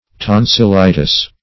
Tonsilitis \Ton`sil*i"tis\, n. [NL. See Tonsil, and -itis.]